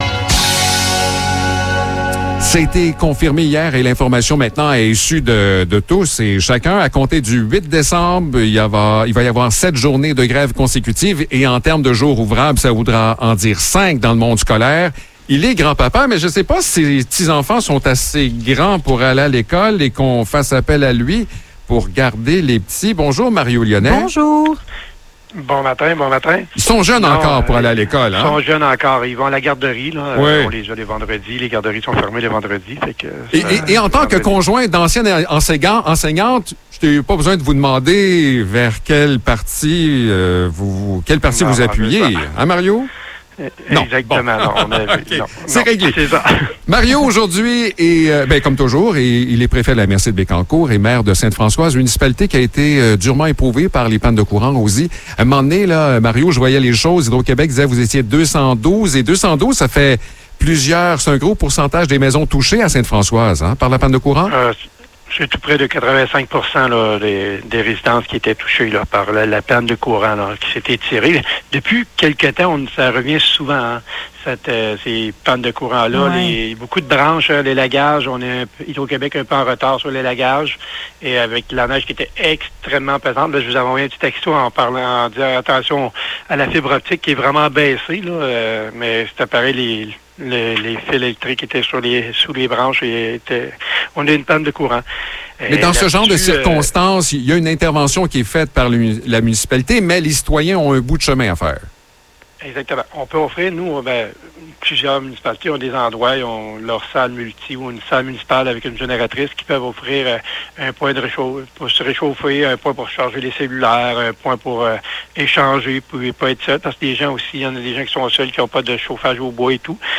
Échange avec Mario Lyonnais
Mario Lyonnais est Maire de Ste-Françoise et Préfet de la MRC de Bécancour. Il nous parle des dernières nouvelles, dont la panne de courant prolongée de cette semaine.